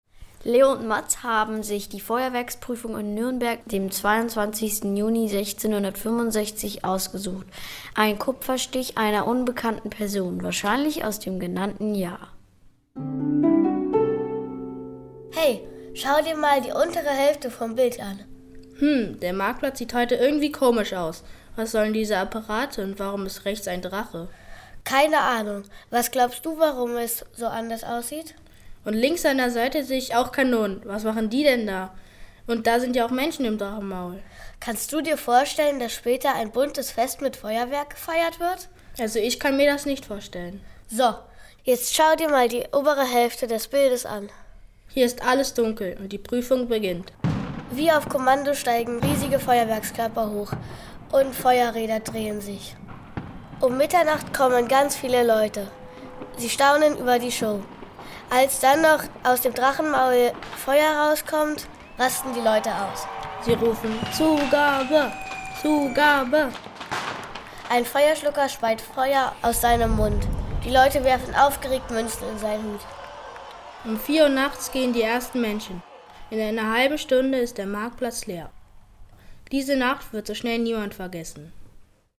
Audioguide | Kunstbibliothek